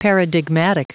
Prononciation du mot paradigmatic en anglais (fichier audio)
paradigmatic.wav